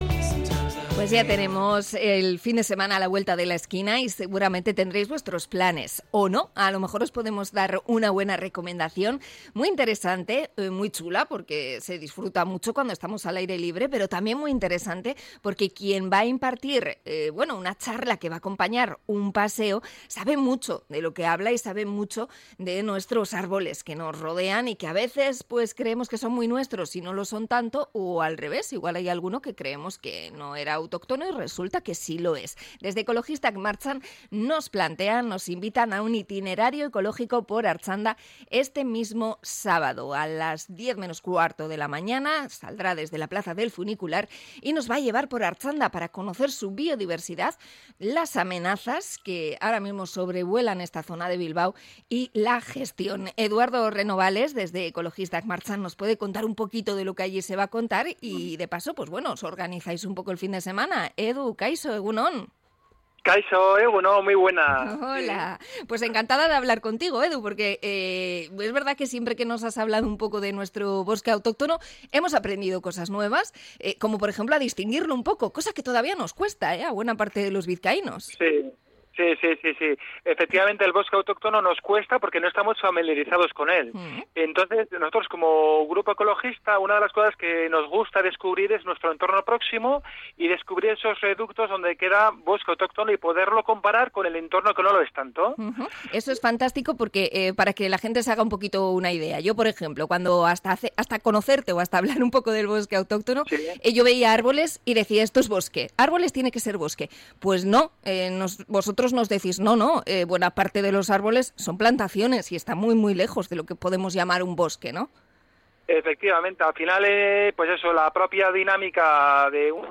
Entrevista a Ekologistak Martxan por la excursión de este sábado a Artxanda